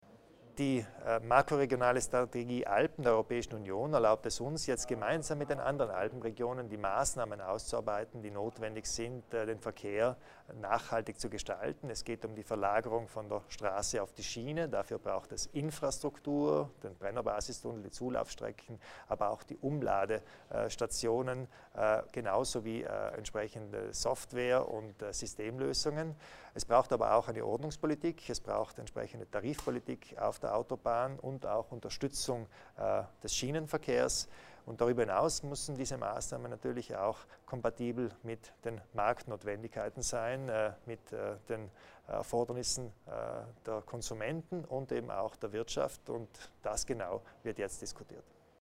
Landeshauptmann Arno Kompatscher erläutert die Bedeutung einer gemeinsamen Strategie im Personen- und Güterverkeh